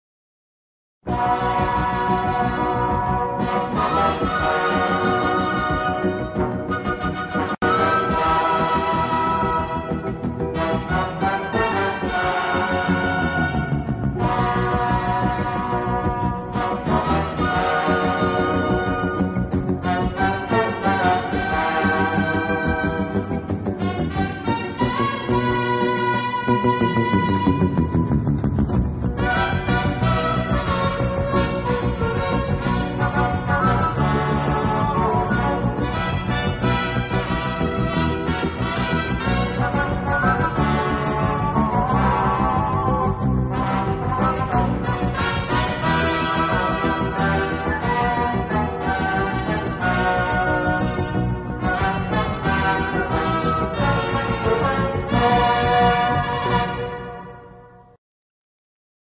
سرودهای دهه فجر
بی‌کلام